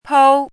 chinese-voice - 汉字语音库
pou1.mp3